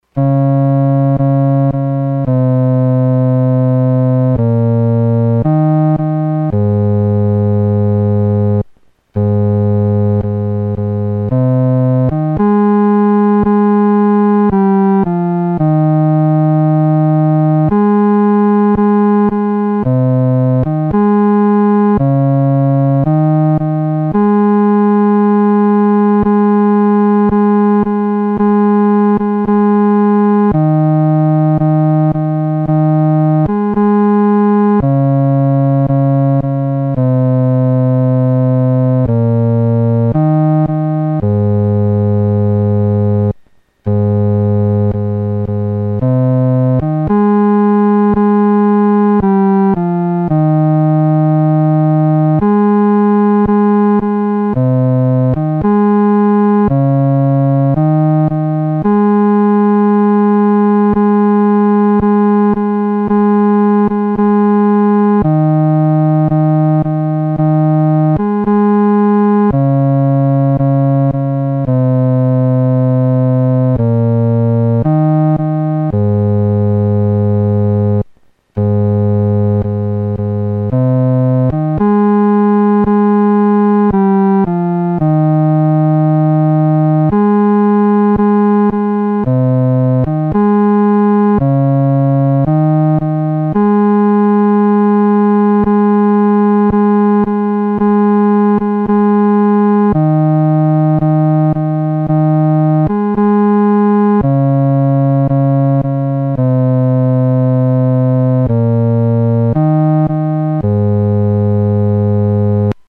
独奏（第四声）